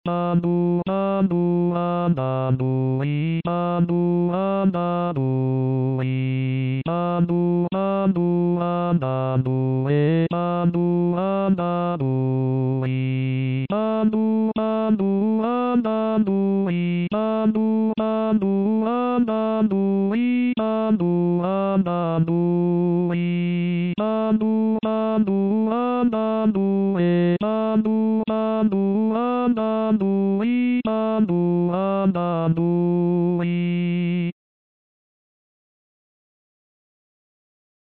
CONTRALTI